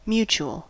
Ääntäminen
Synonyymit common concerted reciprocal Ääntäminen US RP : IPA : /ˈmjuːt͡ʃuəl/ Lyhenteet ja supistumat mut.